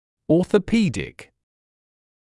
[ˌɔːθə’piːdɪk][ˌо:сэ’пи:дик]ортопедический